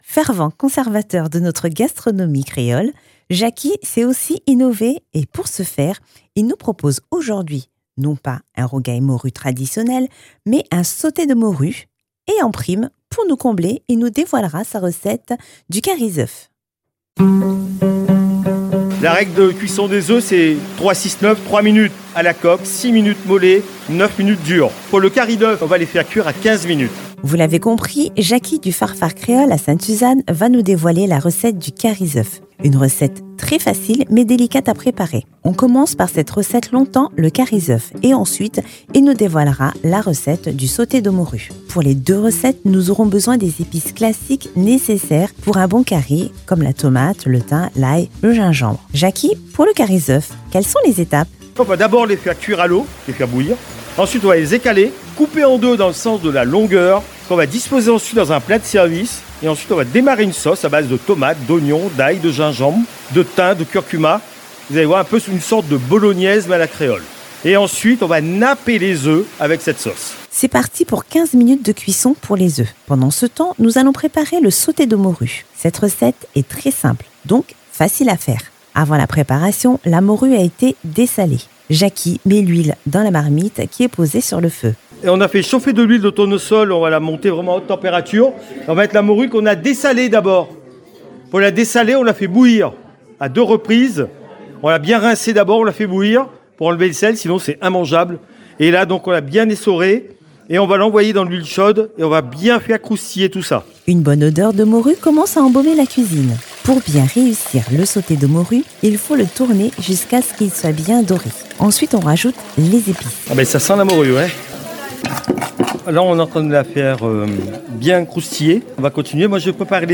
Tout commence avec un rendez-vous au marché, que ce soit celui du Chaudron, de Sainte-Suzanne, ou d’ailleurs.